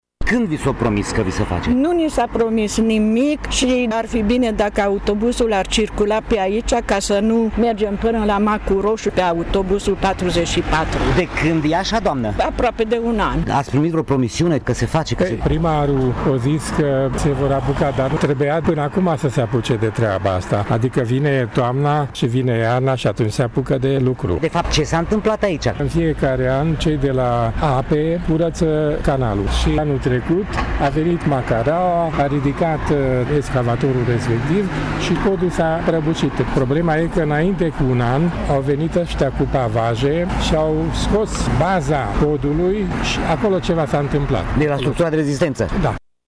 Locuitorii din zonă sunt nemulțumiți și așteaptă ca administrația locală să le repare podul: